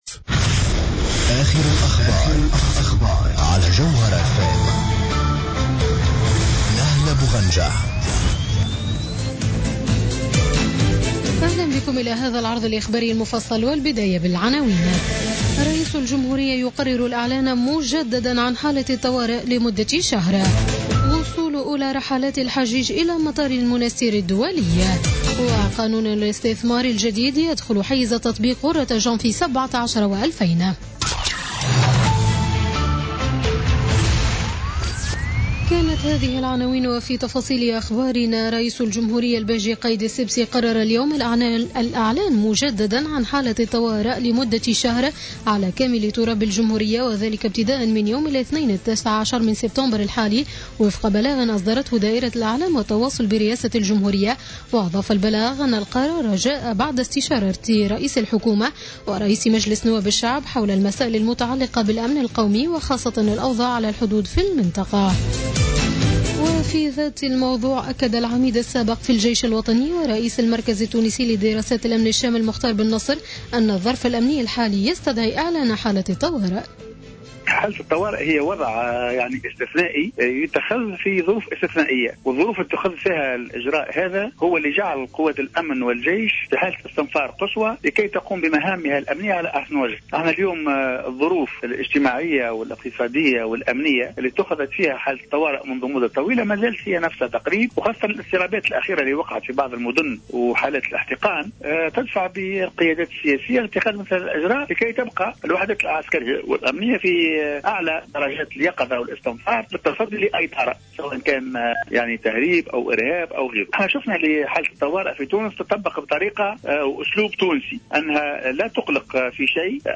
نشرة أخبار السابعة مساء ليوم السبت 17 سبتمبر 2016